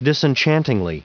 Prononciation du mot disenchantingly en anglais (fichier audio)
Prononciation du mot : disenchantingly